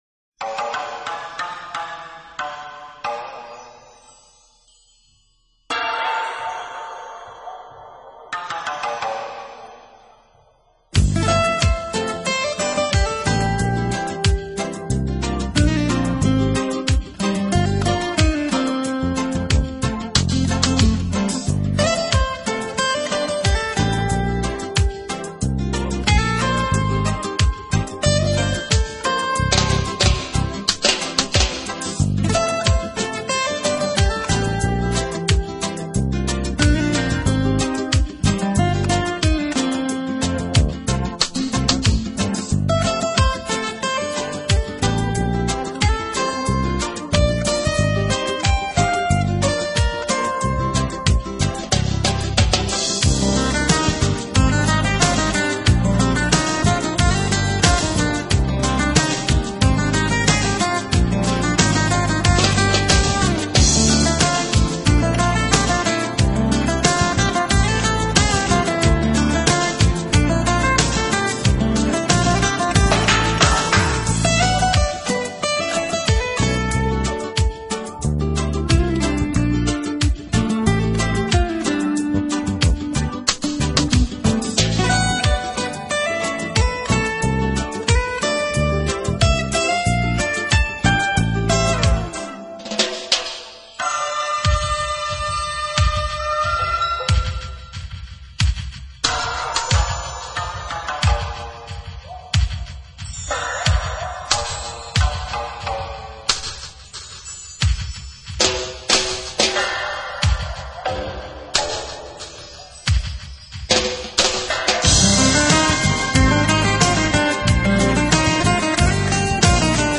音乐类型：Jazz 爵士
音乐风格：NEWAGE，Smooth Jazz，Contemporary，Instrumental